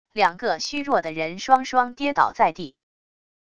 两个虚弱的人双双跌倒在地wav音频